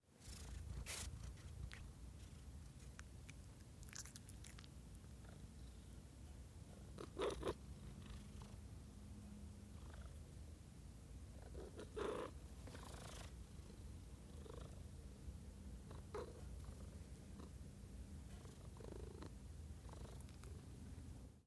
Animals » Cat single meow 2
描述：Recording of my cat meowing, recorded on Tascam HDP2.
标签： cat animal pet meow
声道立体声